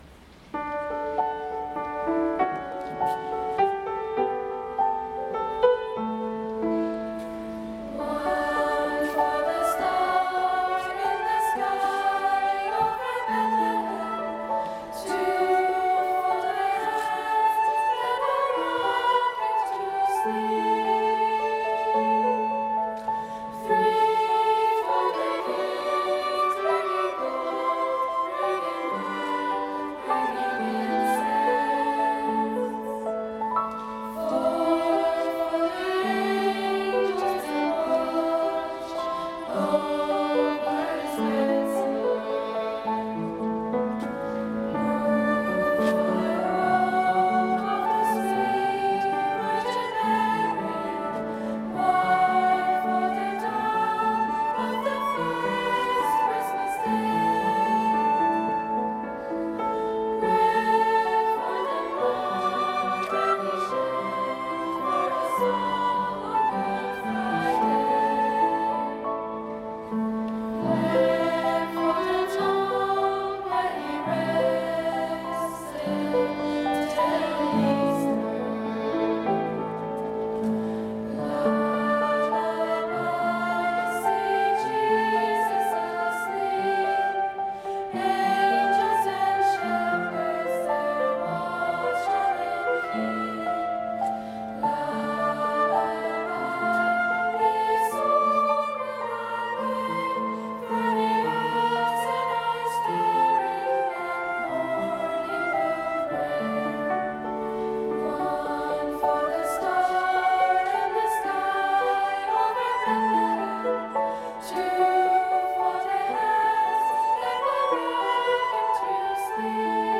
Mit wunderbaren Chorklängen, sanften Gitarren- und Bandsounds, atmosphärischer orchestraler Sinfonik und Orgelmusik von der Empore stimmten uns die großen musikalischen Ensembles am 11. Dezember in St. Gabriel auf Weihnachten 2024 ein.
"Carol of the Children" von John Rutter, Vokalensemble Jgst. 8-12